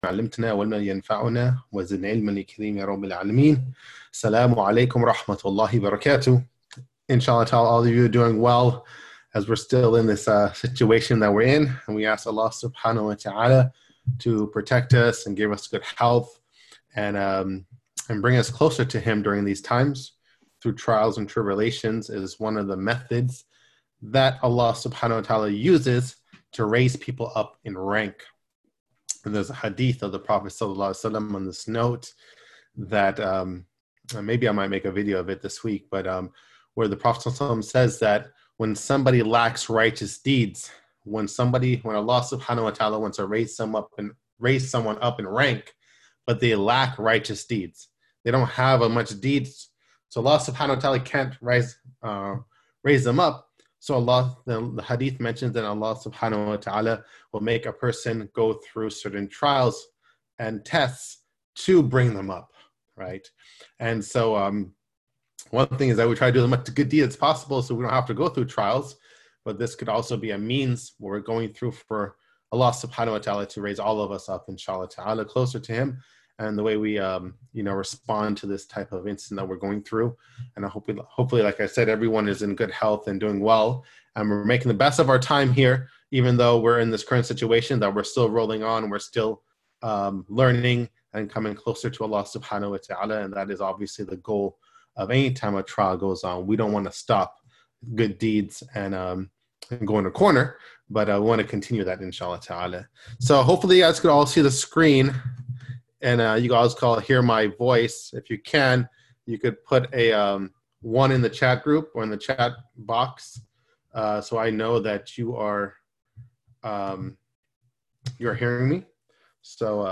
Seerah Class